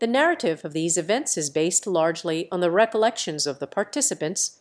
Matcha-TTS - [ICASSP 2024] 🍵 Matcha-TTS: A fast TTS architecture with conditional flow matching
VITS_2.wav